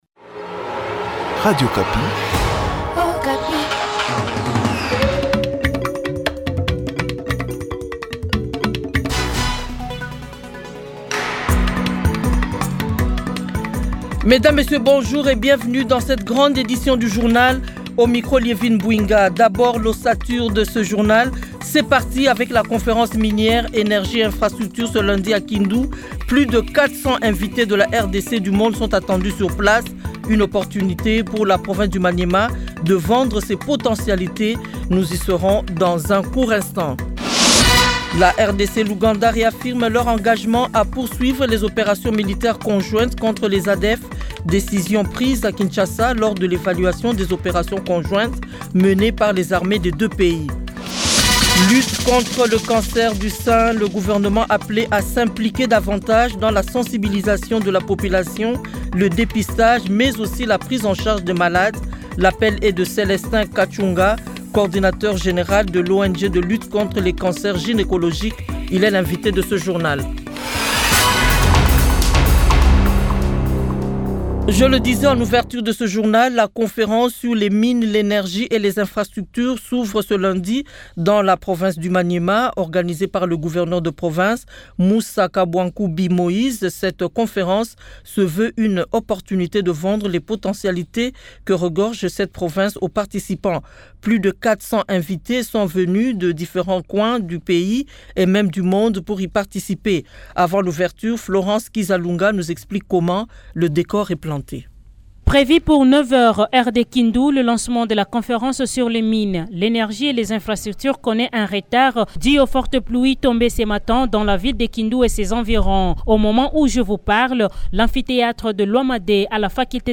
Journal Français Midi